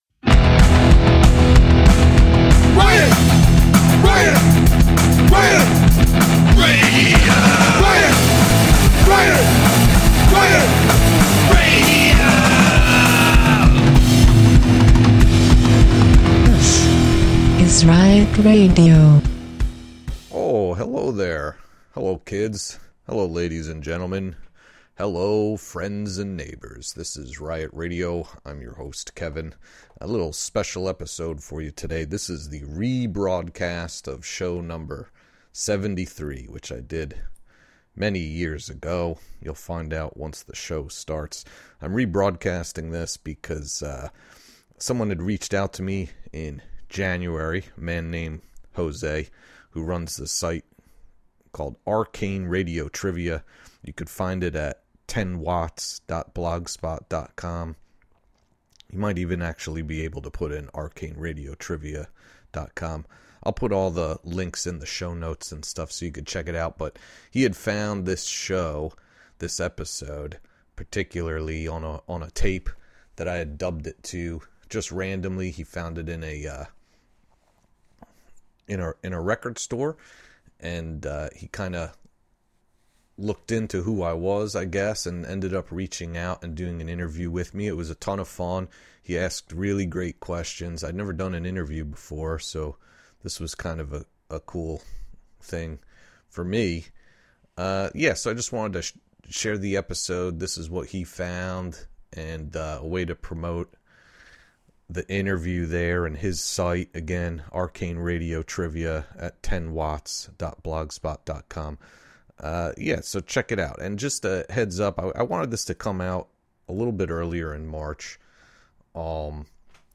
Local Punk and Oi! from around the world.